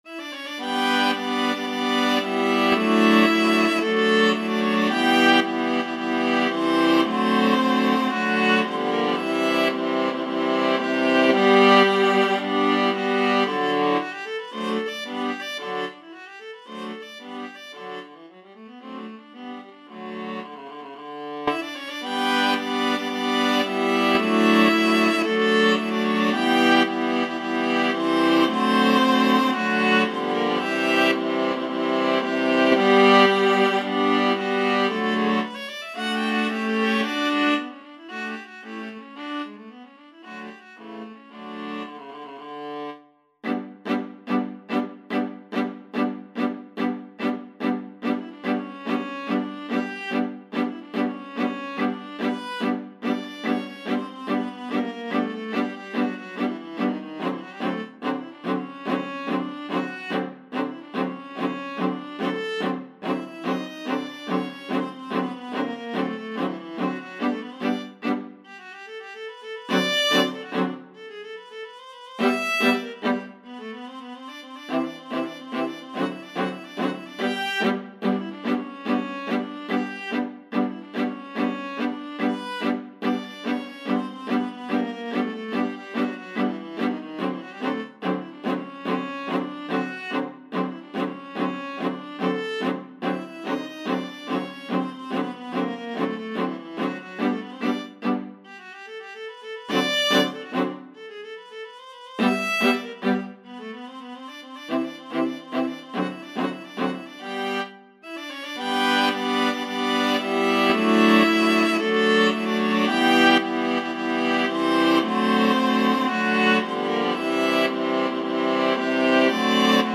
4/4 (View more 4/4 Music)
Maestoso marziale = c. 112
Classical (View more Classical Viola Ensemble Music)